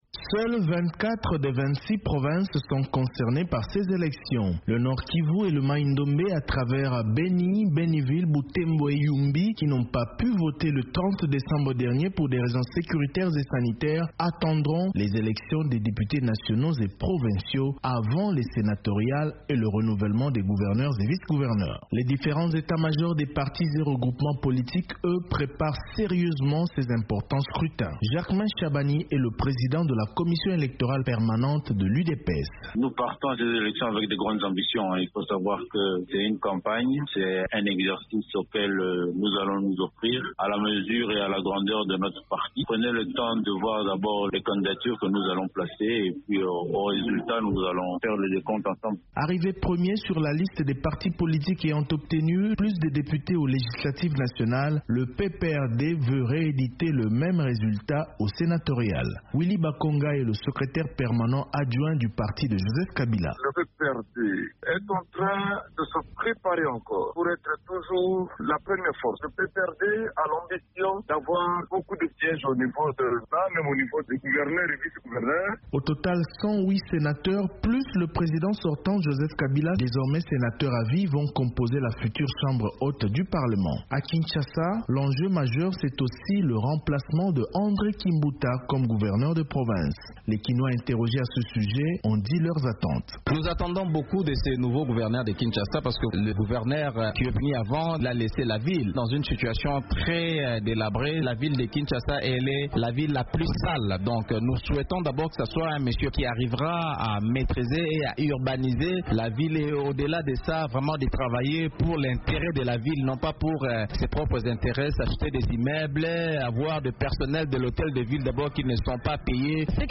Et en attendant le début de la campagne électorale et le jour du scrutin, les différents états-majors se préparent. Reportage